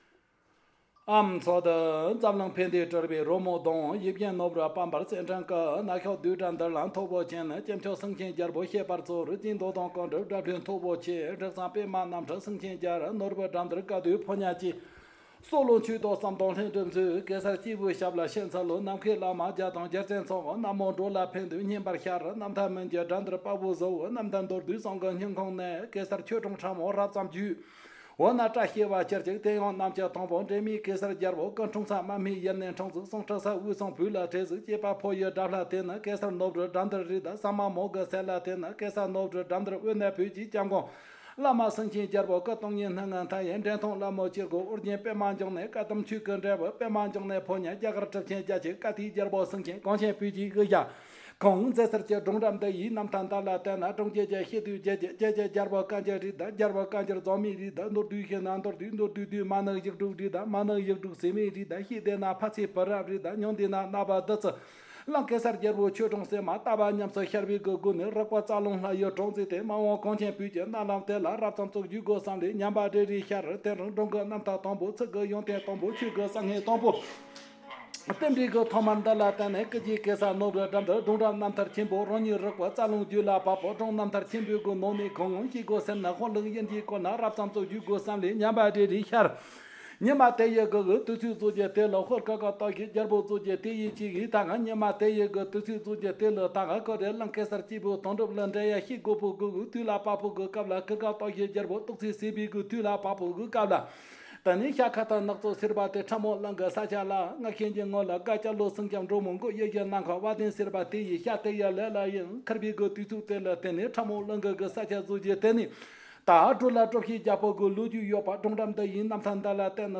Babdrung are said to have been inspired through a dream in which they meet a deity on the grassland.
Location Description: Vienna hotel (Museum branch) in Yushu Tibetan Autonomous Prefecture